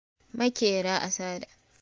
Pronunciation of Makera Assada